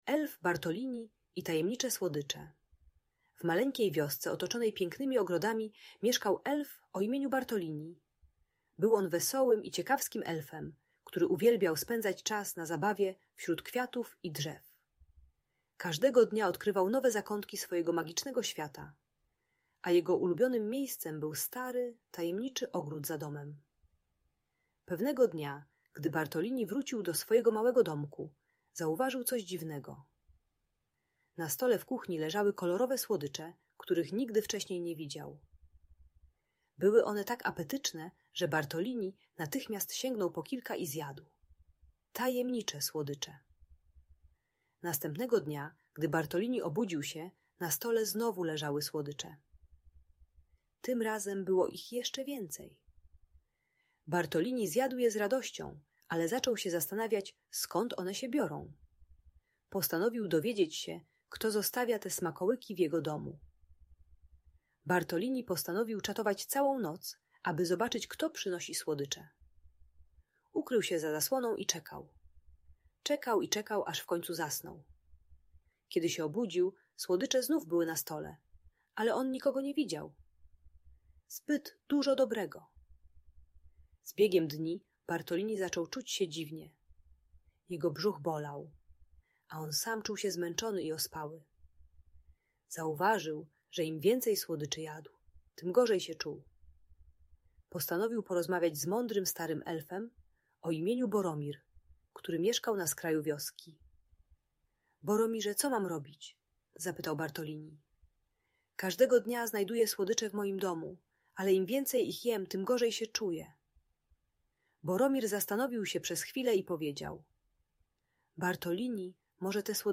Elf Bartolini i Tajemnicze Słodycze - magiczna historia - Audiobajka dla dzieci